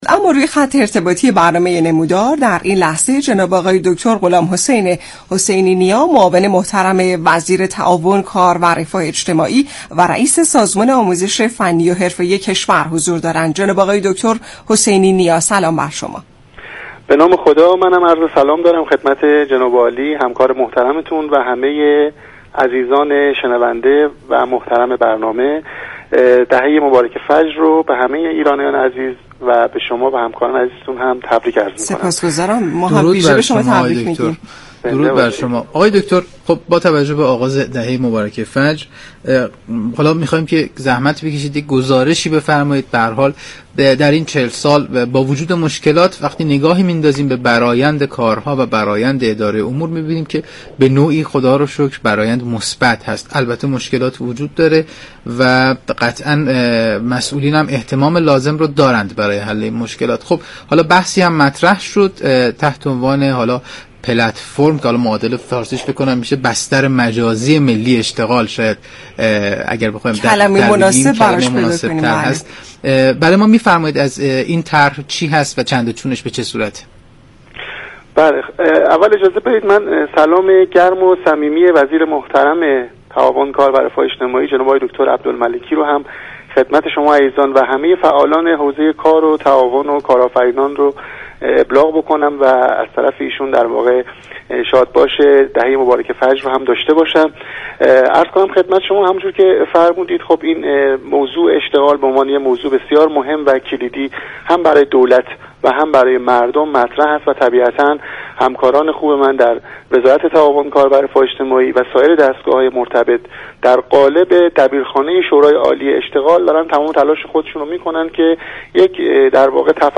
به گزارش شبكه رادیویی ایران، غلامحسین حسینی نیا معاون وزیر تعاون، كار و رفاه اجتماعی در برنامه «نمودار» درباره جزئیات طرح بستر مجازی ملی اشتغال گفت: سامانه بستر مجازی ملی اشتغال توسط شورای عالی اشتغال راه اندازی شده است این سامانه با شناسایی نقاط ضعف بستر اطلاعات مناسبی برای بازار كاراست.
برنامه نمودار شنبه تا چهارشنبه هر هفته ساعت 10:20 از رادیو ایران پخش می شود.